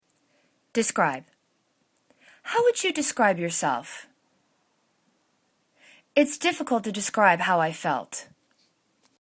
de.scribe    /di'skri:b/     [T]